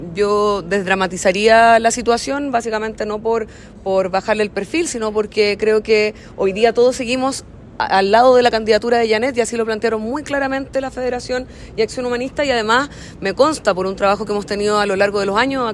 Frente a este escenario, la presidenta del Frente Amplio, Constanza Martínez, desdramatizó la situación, apuntando a que la unidad aún persiste.